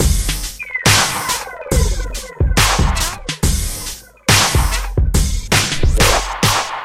描述：一个老吉他的家伙弹吉他，唱着楼下录音;漂亮的大厅
标签： 播放 家伙 地铁 牙买加 吉他 伙计 离开 记录 到目前为止
声道立体声